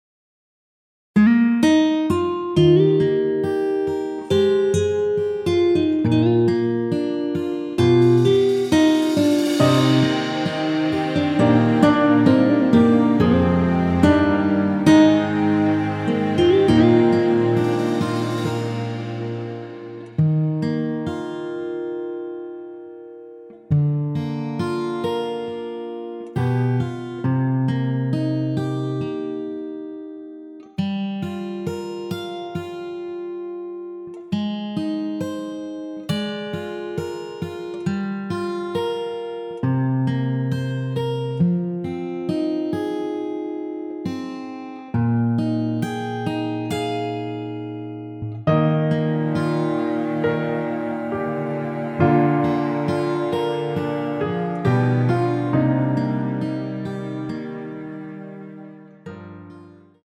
원키에서(-5)내린 MR입니다.
Eb
앞부분30초, 뒷부분30초씩 편집해서 올려 드리고 있습니다.